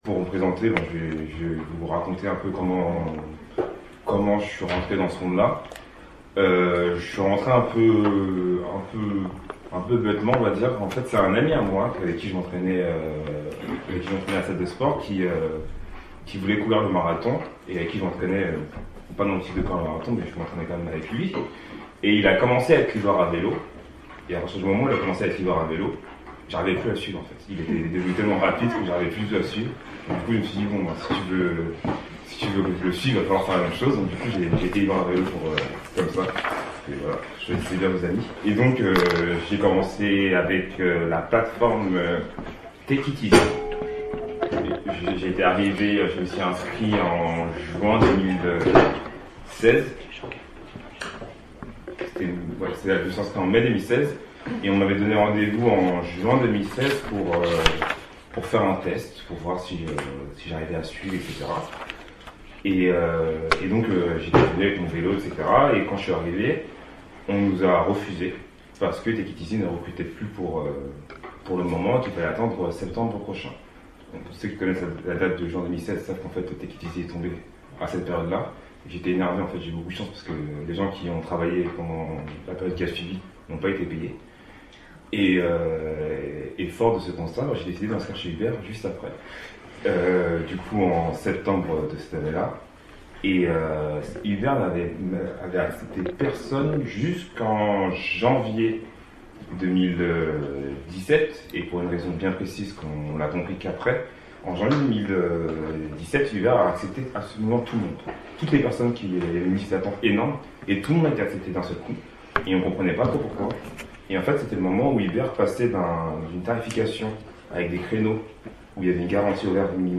Cette séance, enregistrée dans le cadre du séminaire annuel Pratiques et pensées de l'émancipation de la MRSH aborde les modalités d’organisation et de lutte des livreurs à vélo au regard de leurs conditions de travail, de rémunération et d’emploi. S’intéresser à ces mobilisations permet à la fois de saisir les mutations contemporaines du capitalisme et du droit du travail en France mais aussi d’analyser les dimensions innovantes ou réactualisées de l’action collective dans le monde du travail.